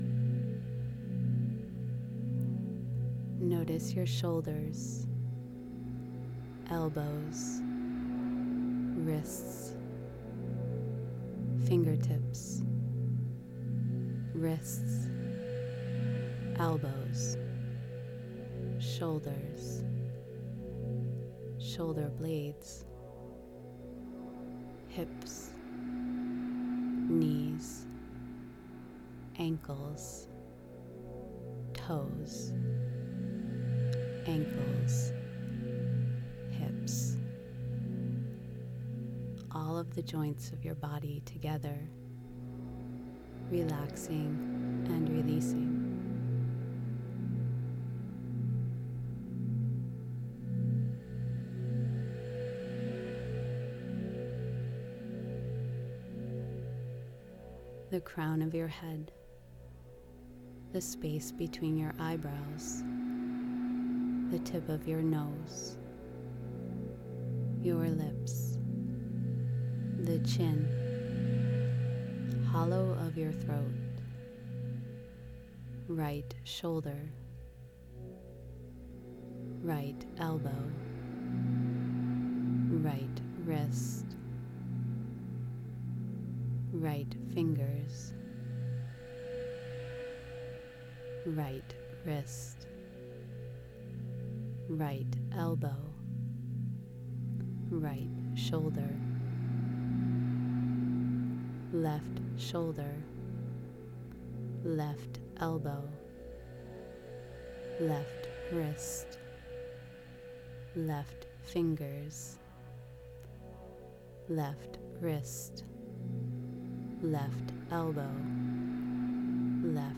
Relaxation and Yoga Nidra